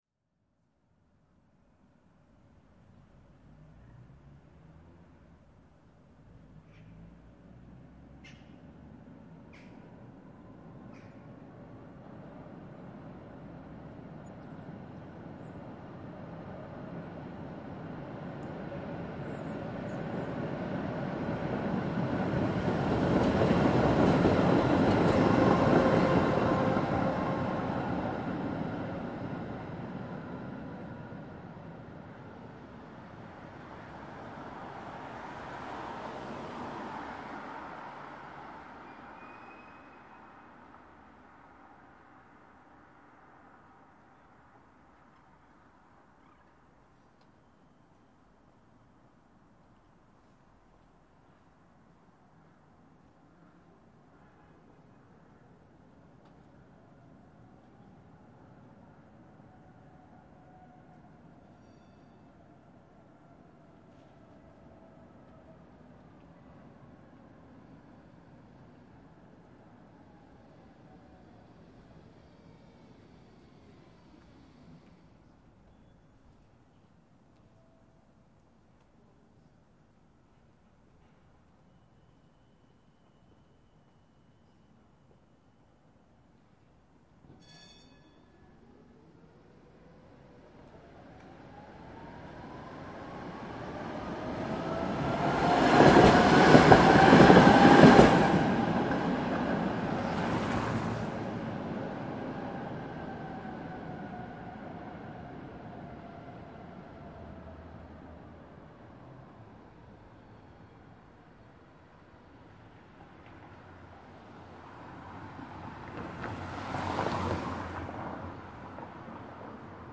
描述：这个用奥林巴斯WS550M录制的声音是菲格雷斯的主要街道在一个典型的集市上的声音.
标签： 环境 街道
声道立体声